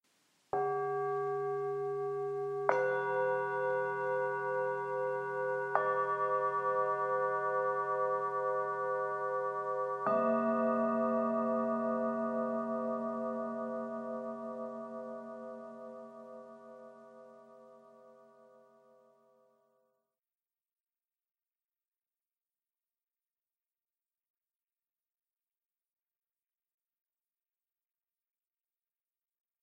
Tibetan_Bowls.mp3